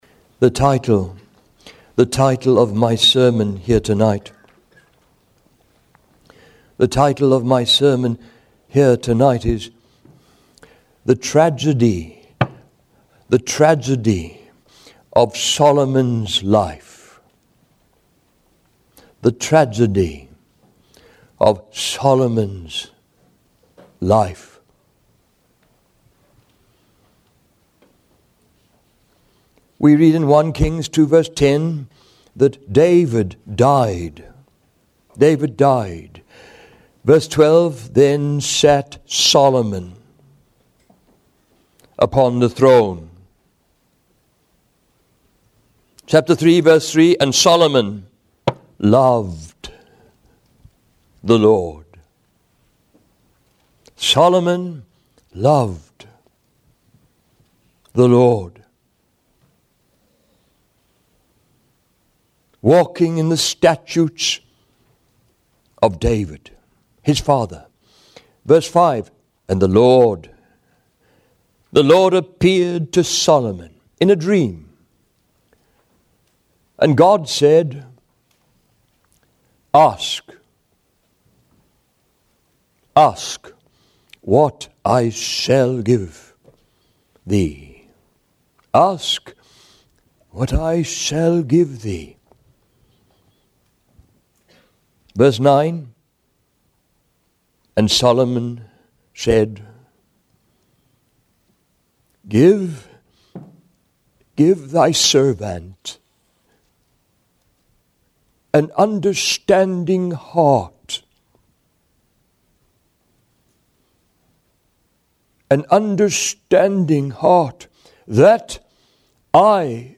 In this sermon, the speaker shares the story of his father's transformation after finding God.